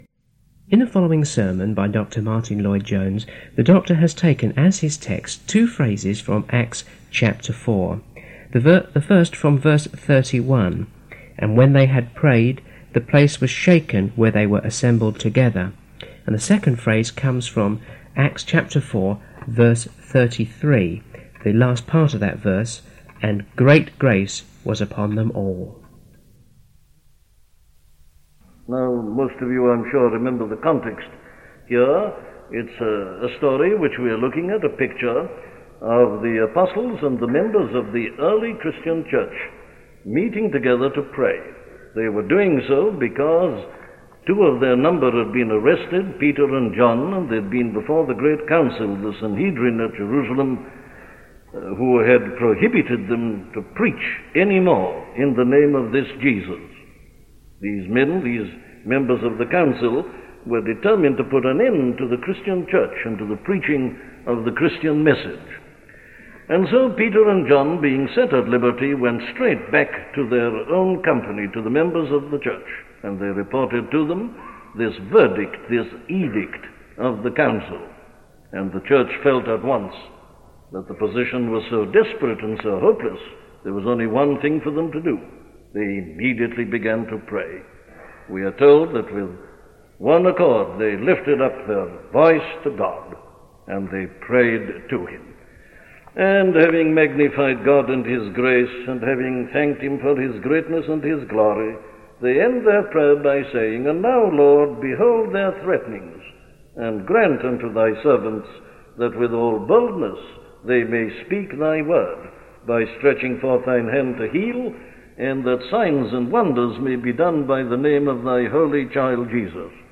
The God of Grace and Power - a sermon from Dr. Martyn Lloyd Jones